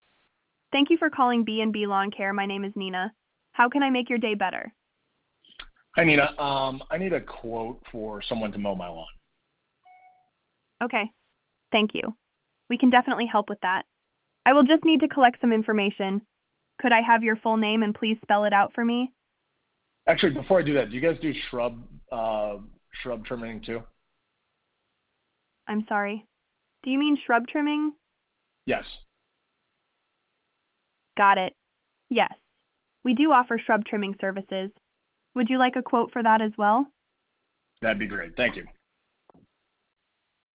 B-and-B-Lawn-Care-AI-Voice-Sample.wav